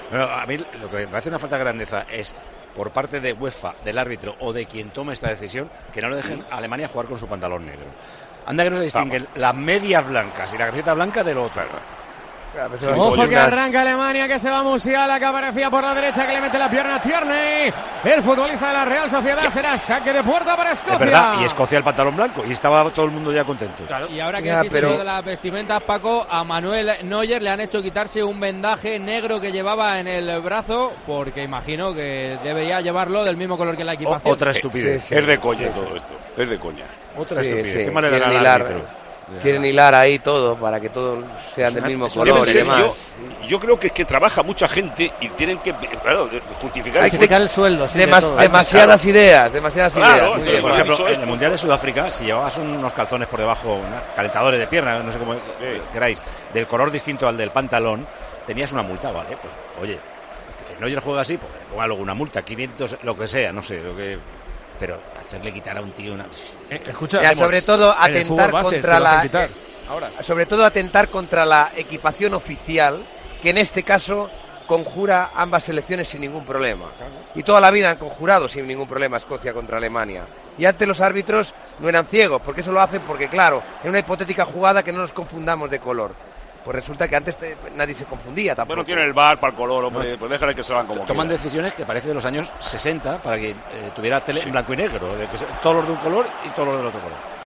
En el siguiente audio podrás escuchar íntegro el debate sobre las equipaciones y la opinión de Paco sobre las indumentarias.